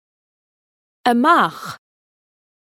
Amazon AWS (pronunciation "a mach" [NB: Unfortunately I haven't found a link to the noun]).